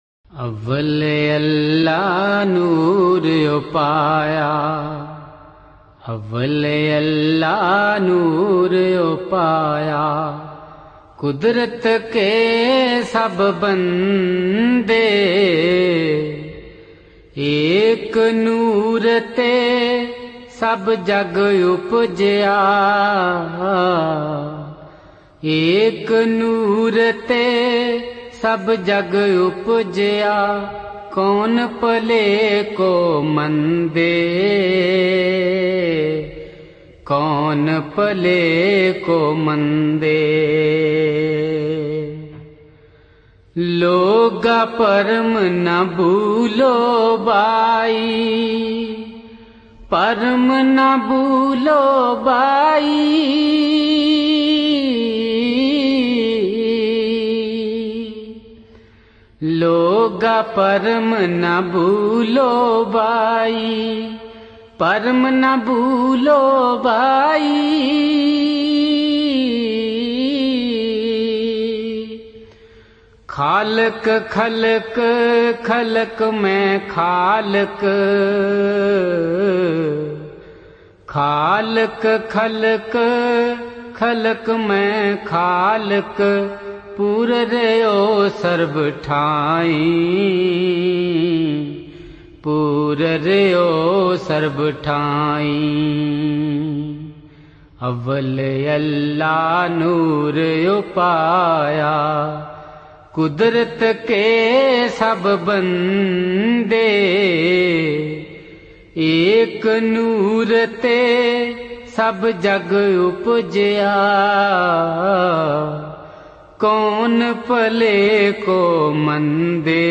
Awwal Allah Noor Upaya Kudra-Radhasoami Shabad _ Radha Soami Surat Sabd Yog.mp3